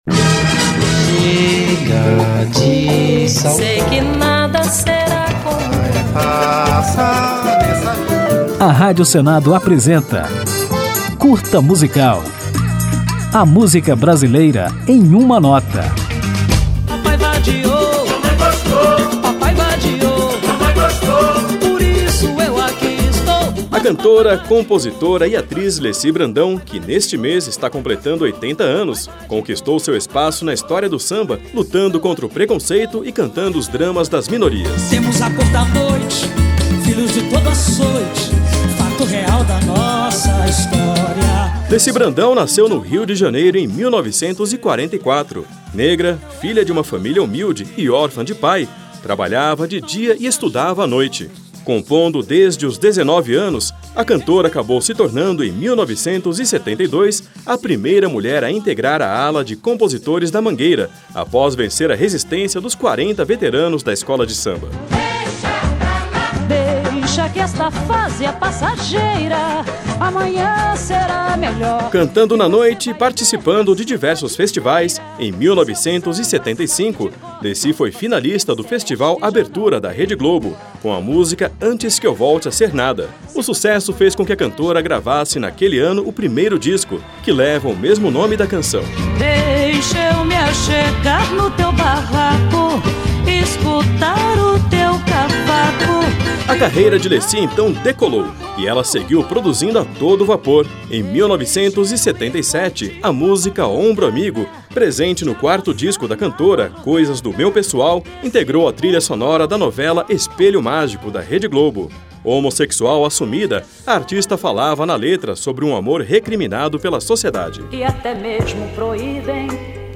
Para comemorar, o Curta Musical presta uma homenagem à artista e apresenta um pouco da obra desta grande sambista, conhecida pelo engajamento, pelas letras de temática social e pelo samba de altíssima qualidade. Ao final do programa ouviremos Zé do Caroço, um clássico de Leci Brandão que nunca sai de moda.
Samba